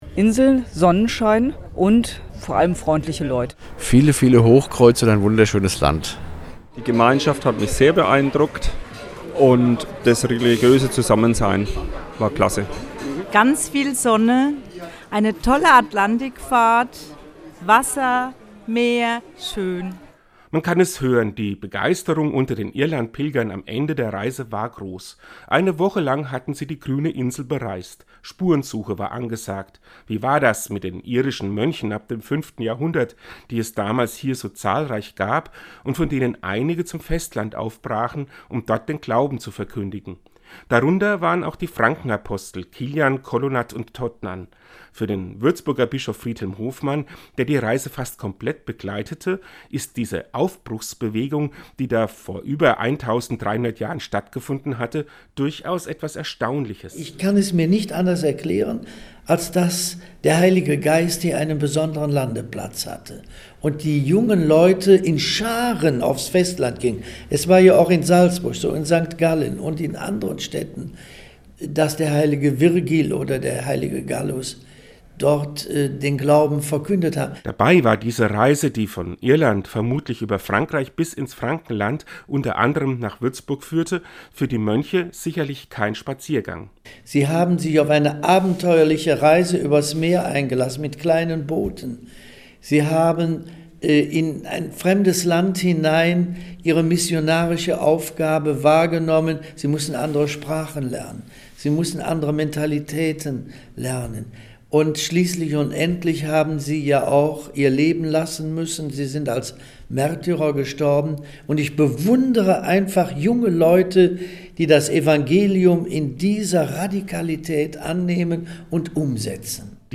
Den Radiobeitrag finden Sie unten als Download!